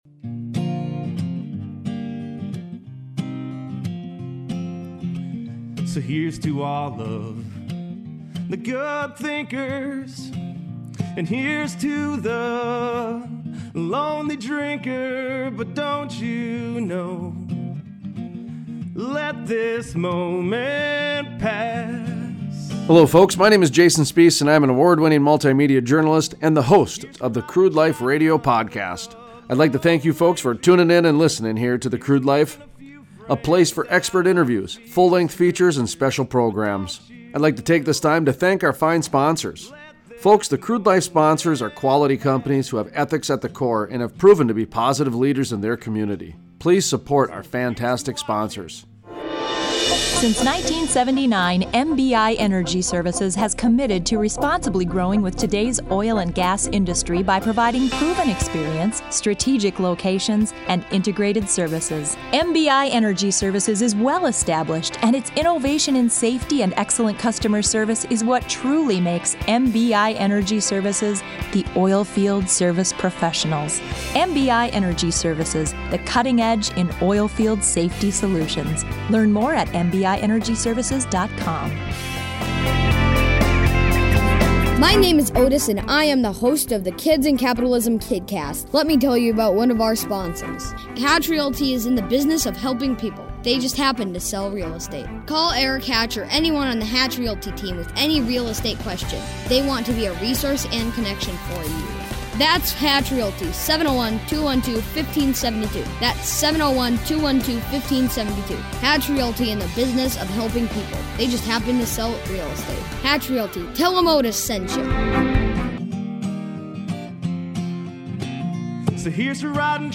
Full Length Interviews NDSU President Dean Bresciani (Part 2 of 4) Play Episode Pause Episode Mute/Unmute Episode Rewind 10 Seconds 1x Fast Forward 10 seconds 00:00 / 12 Minutes Subscribe Share RSS Feed Share Link Embed